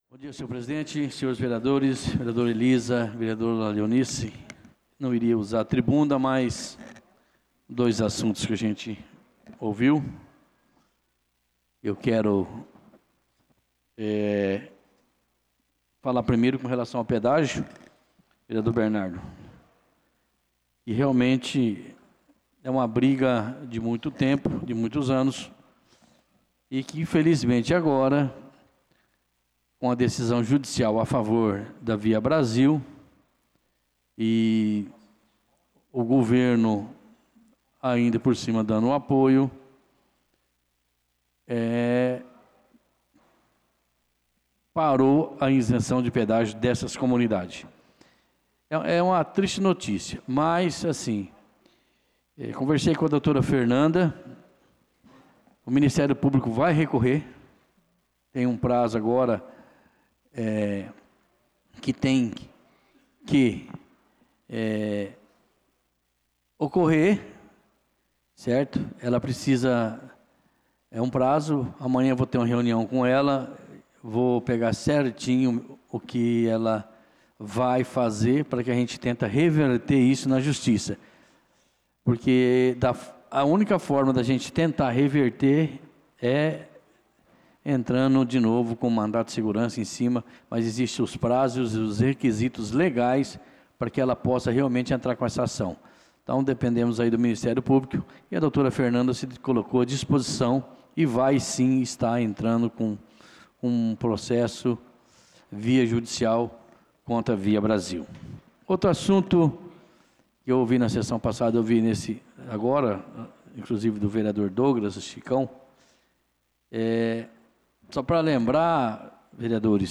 Pronunciamento do vereador Tuti na Sessão Ordinária do dia 05/05/2025